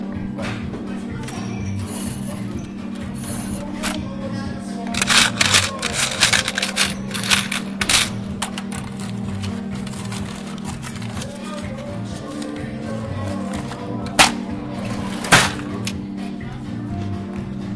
酒吧钱币音乐ST
描述：一个酒吧收银员在数钱。
环境音乐。立体声，wav，48KHz，24bits.